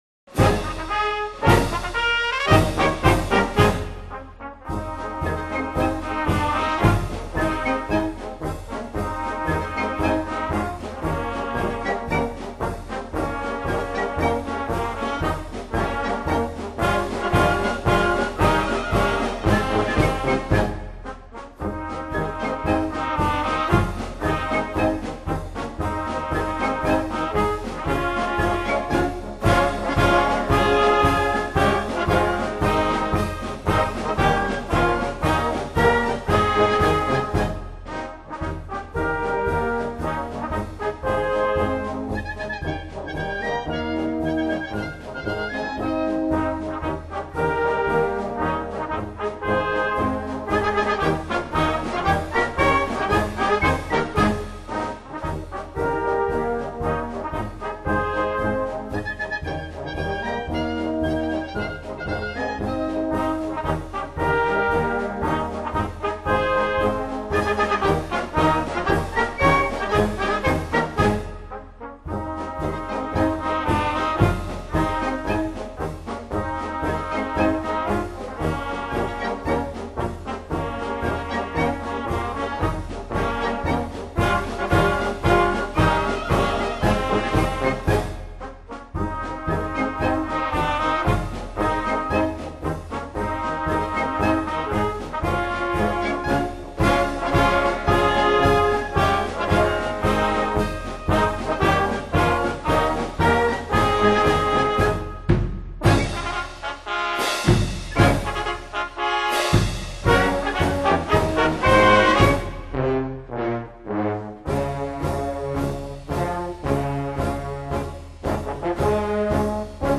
Blasorchester